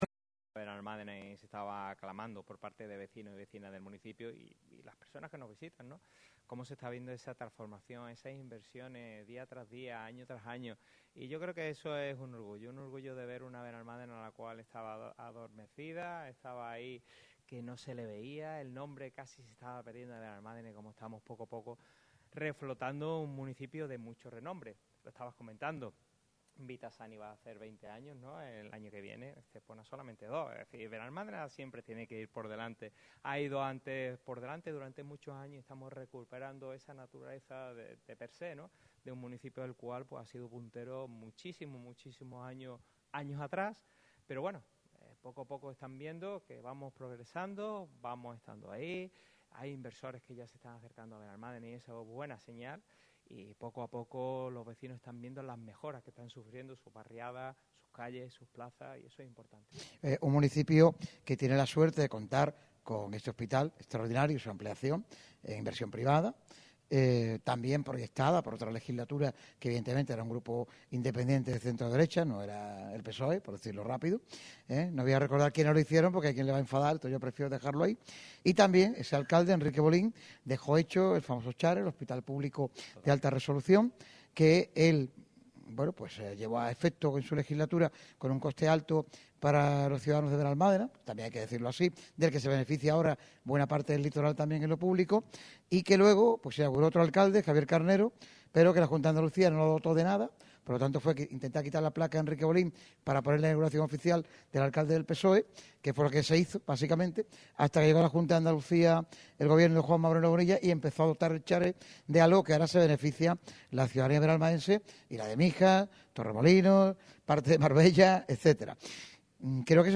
Radio MARCA Málaga ha hecho un programa especial desde las instalaciones del Hospital Vithas Internacional de Benalmádena donde se ha repasado los avances de una empresa dedicada en cuerpo y alma a la salud.